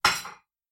拿着银器叉子
描述：把银器叉子，勺子和刀子放在厨房的一个碗里。
标签： 厨房 勺子 银器
声道立体声